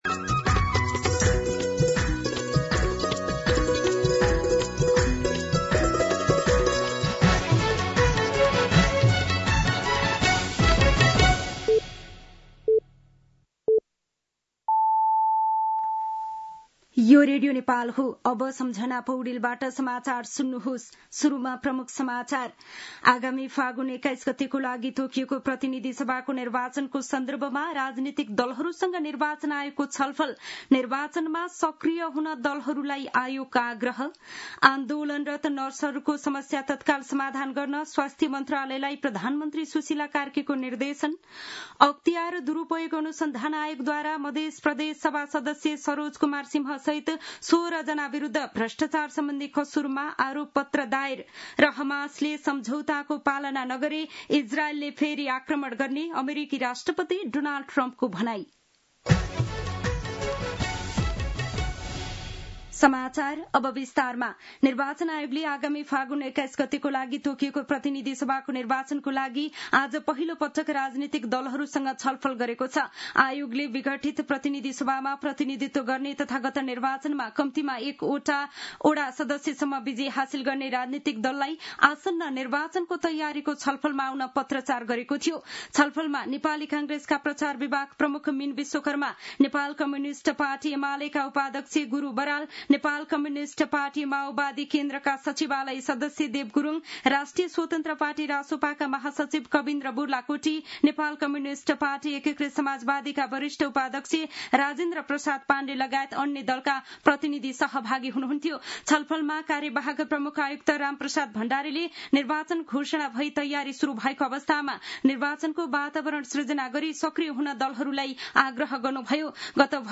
दिउँसो ३ बजेको नेपाली समाचार : ३० असोज , २०८२
3-pm-Nepali-News-7.mp3